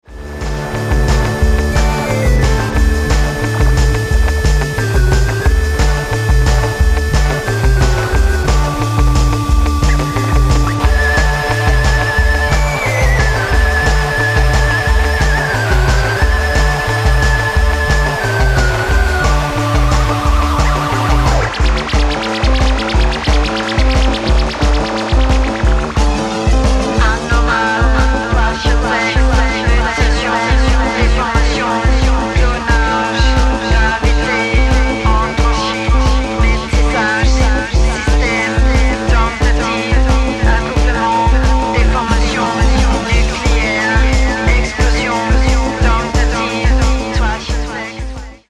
analoge Keyboards, Synthies und Effektgeräte
Rock'n'Roll-Gitarren
klassischem 60s Frauengesang
vocals/recitation
additional guitars